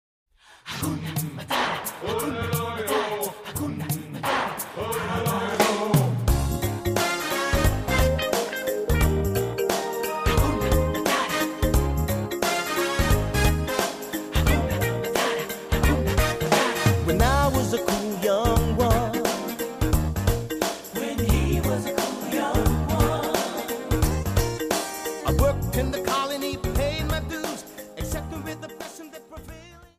Mambo